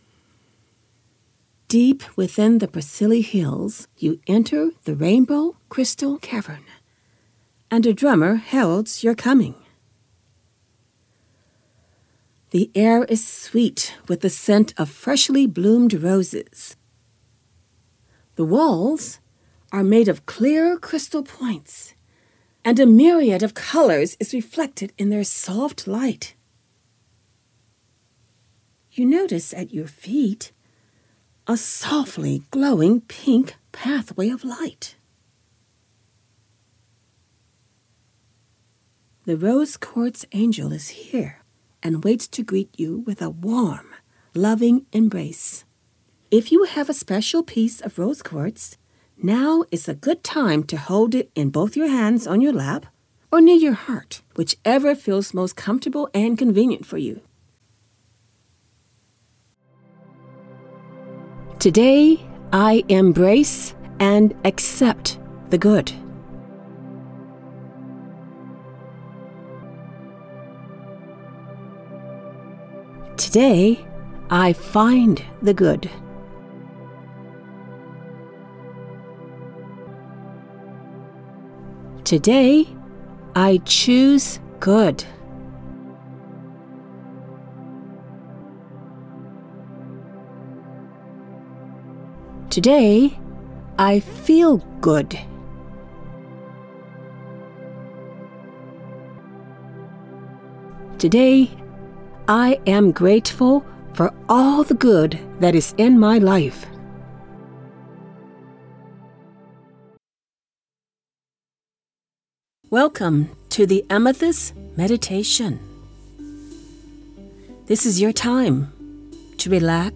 Female
Adult (30-50), Older Sound (50+)
Narration
Meditations & Affirmations